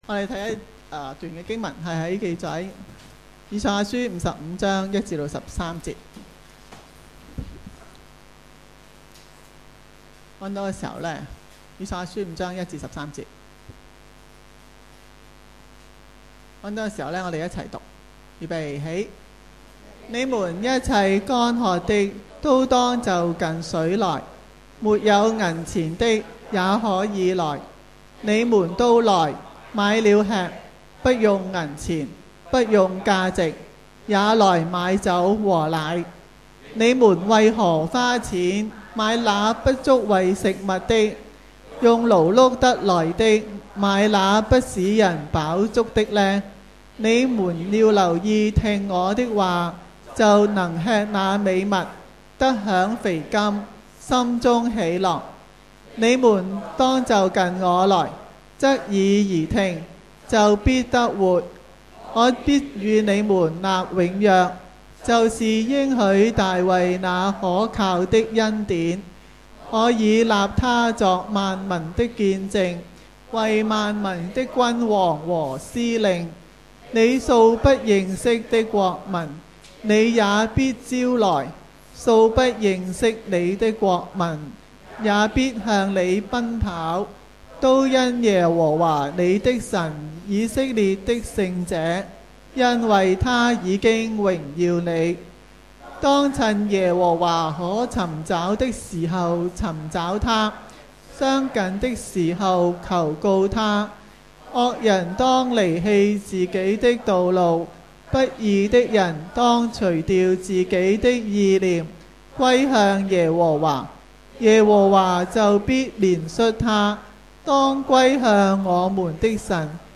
主日崇拜講道-同領上帝恩典